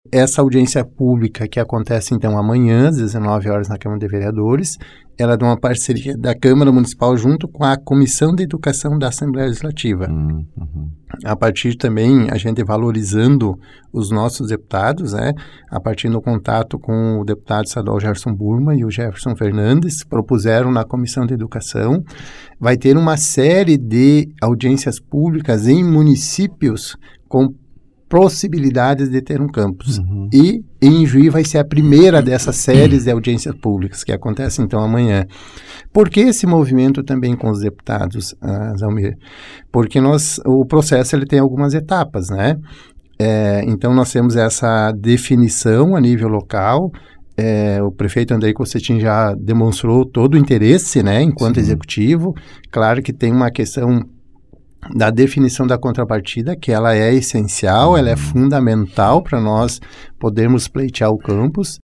Audiência pública será realizada nesta quinta feira na Câmara de vereadores para tratar sobre a possibilidade de instalação de uma unidade do Instituto federal farroupilha em ljuí. Falando hoje no Fatorama o vereador Beto Noronha explicou o andamento destas tratativas.